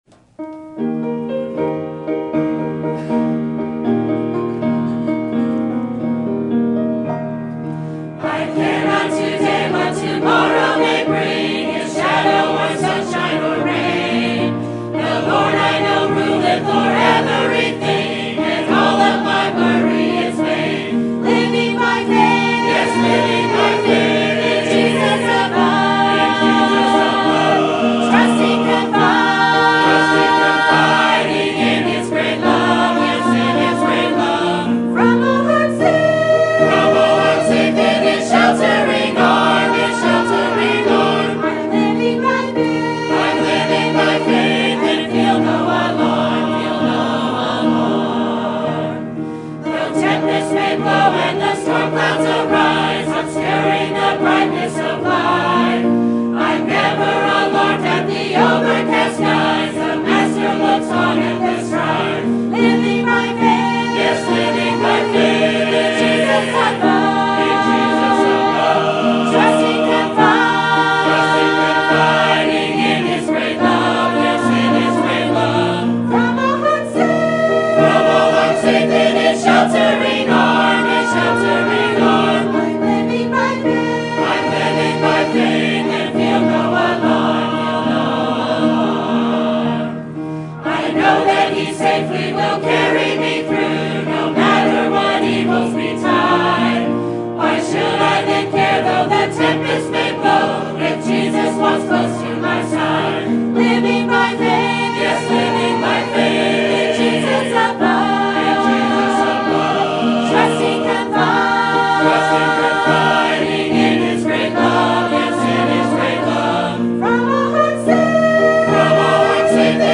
Sermon Date: Sunday, July 15, 2018 - 11:00am Sermon Title: Is It That Hard To Believe, That God Maybe Working In Your Life?